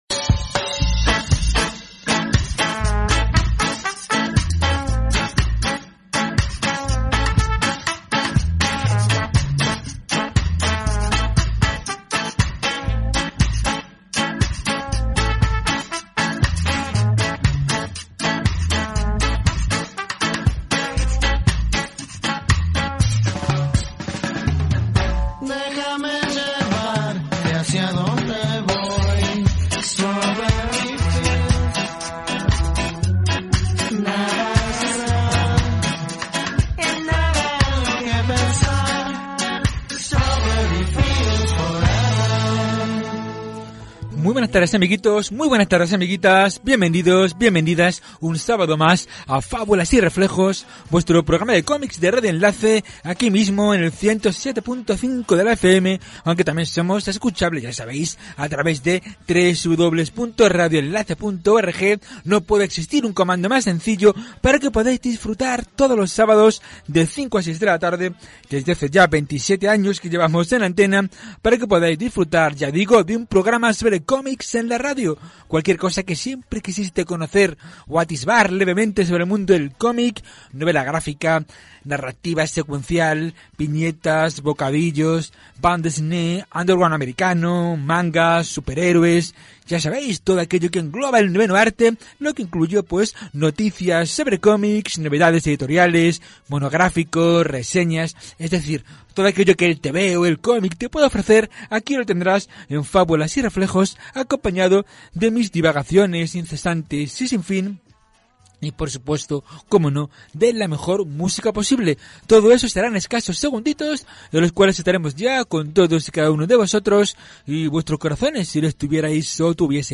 Es por eso que encontrarás un programa cargado de títulos, humor, además de cine, editoriales y cantidad de autores, todo ello siempre acompañado con la mejor música posible...